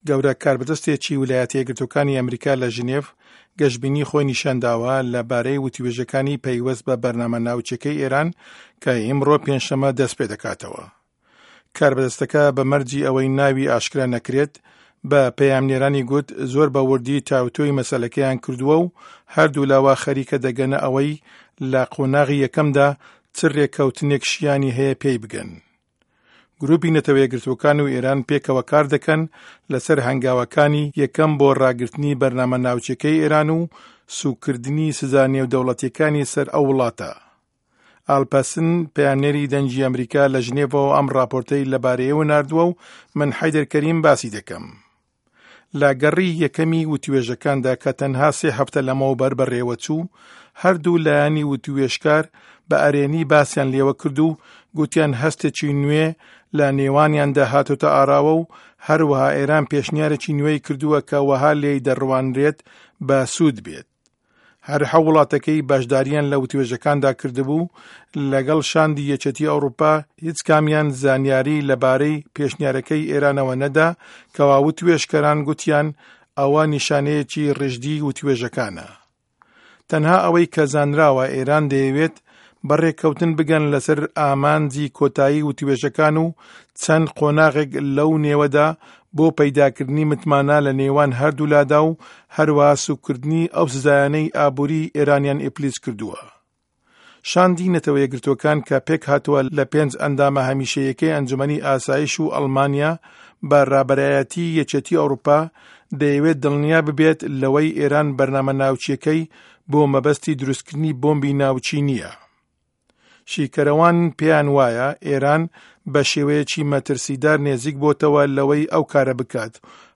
ڕاپـۆرتی ناوکی ئێران